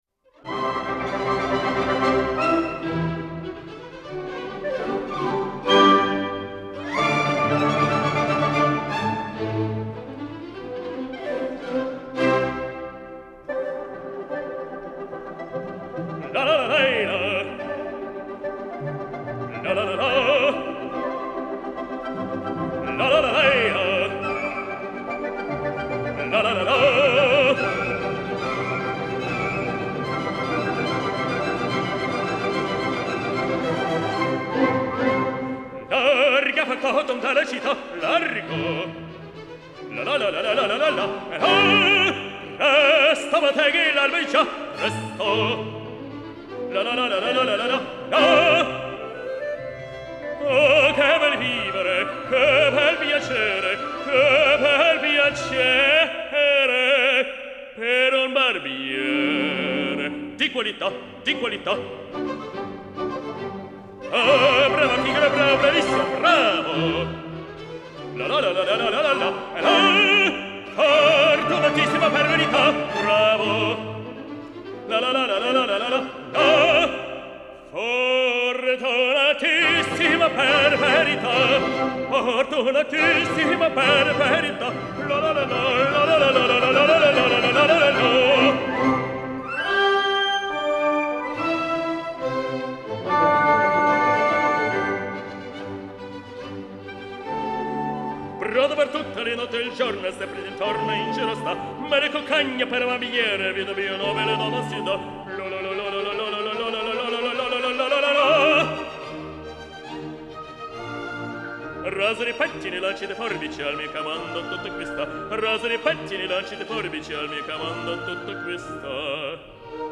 Кавантина Фигаро Опера
Запись на Санкт-Петербургском радио с оркестром